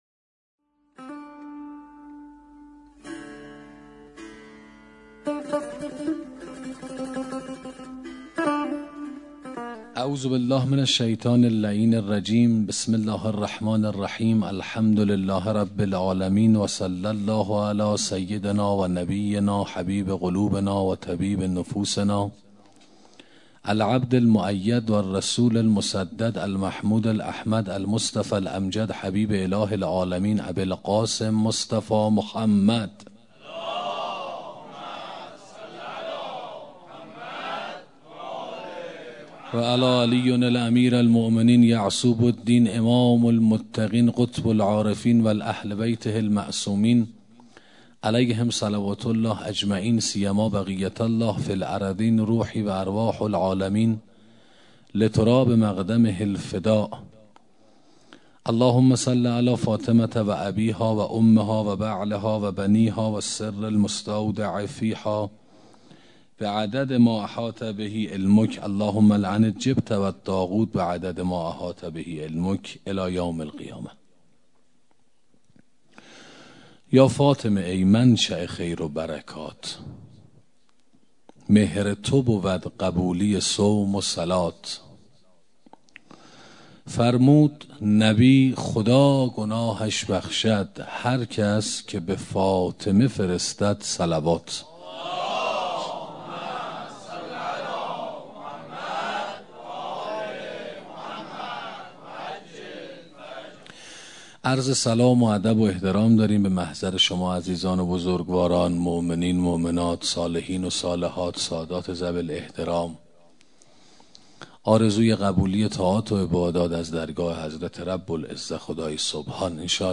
سخنرانی شناخت شخصیتی حضرت زهرا (س) 2 فاطمیه